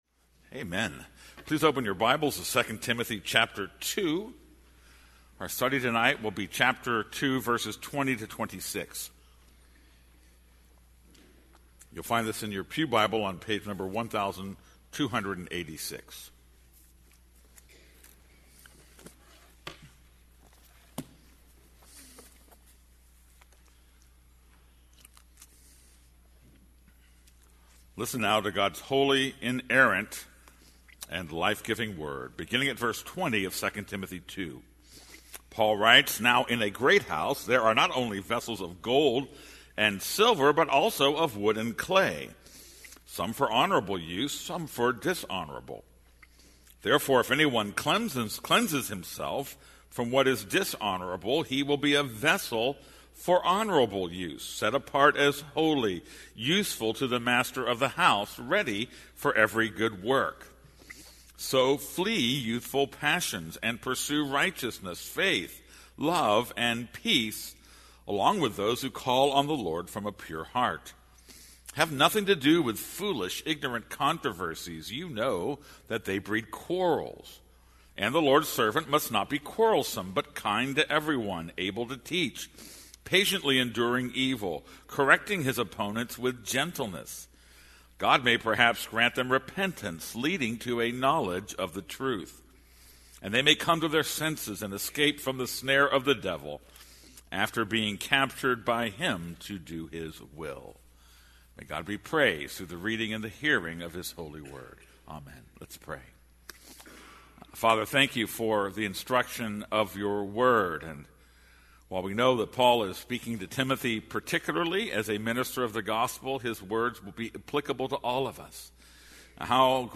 This is a sermon on 2 Timothy 2:20-26.